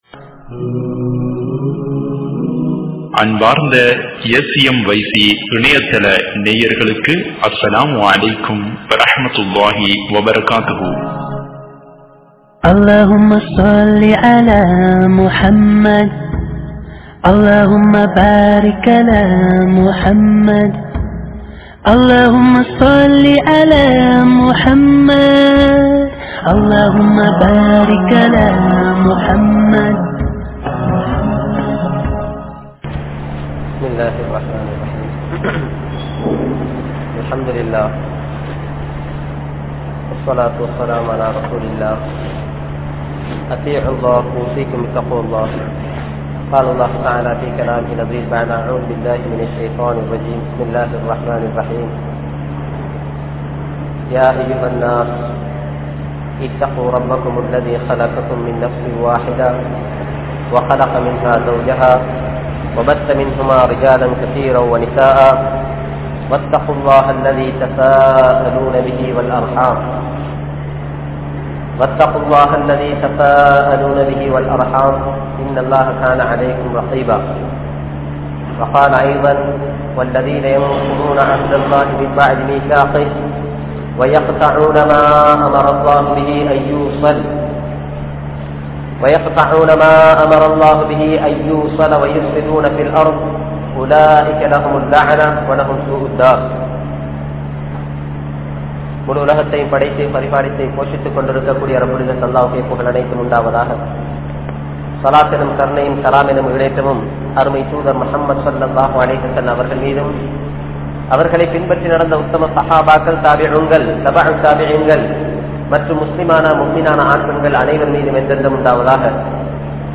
Kudumba Uravuhalai Searnthu Vaalungal (குடும்ப உறவுகளை சேர்ந்து வாழுங்கள்) | Audio Bayans | All Ceylon Muslim Youth Community | Addalaichenai
Muihyadeen Jumua Masjidh